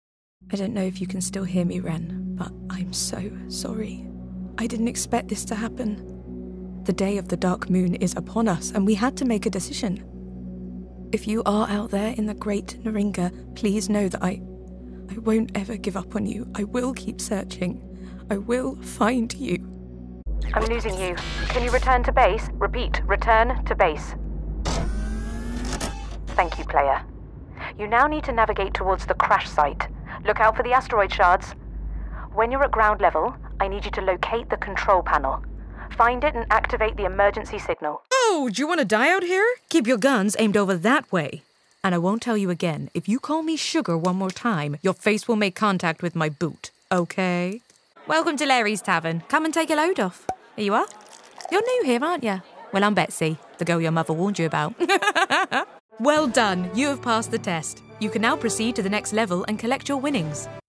Videogame Reel
• Native Accent: RP
a keen ear for accents and an adaptable character voice for games and animation.